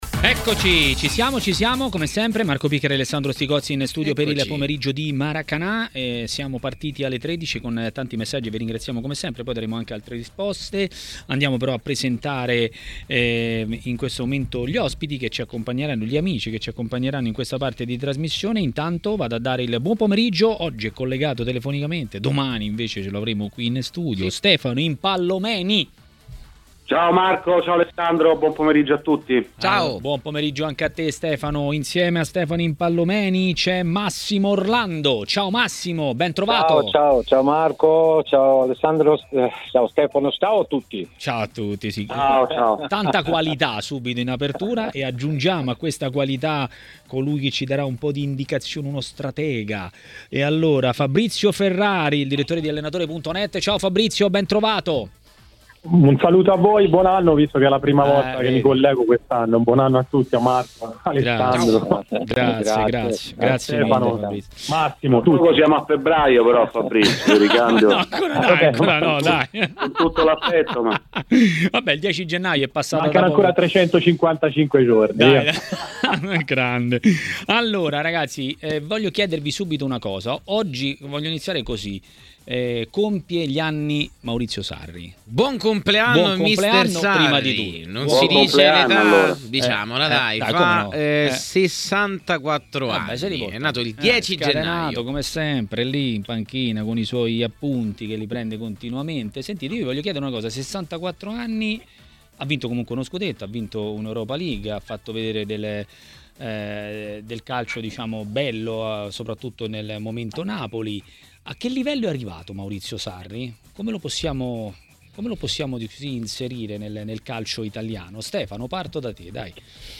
L'ex calciatore Massimo Orlando a TMW Radio, durante Maracanà, ha commentato i temi della Serie A.